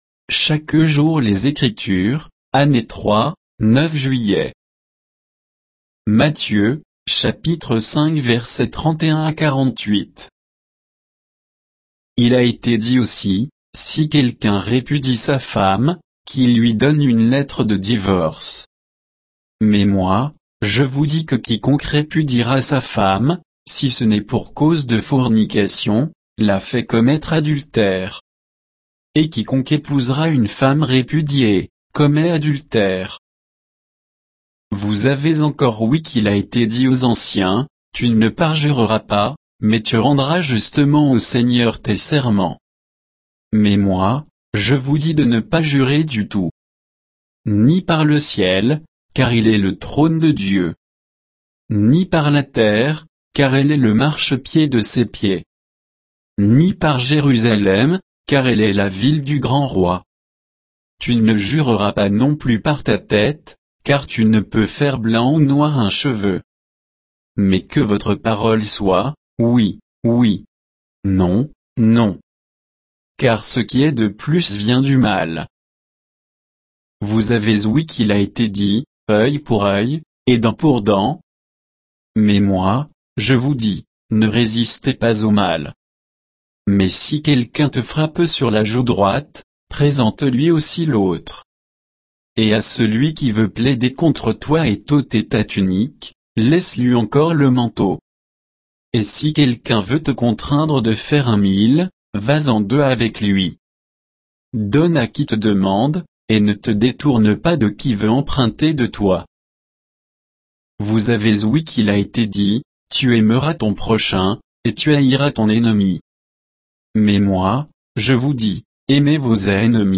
Méditation quoditienne de Chaque jour les Écritures sur Matthieu 5, 31 à 48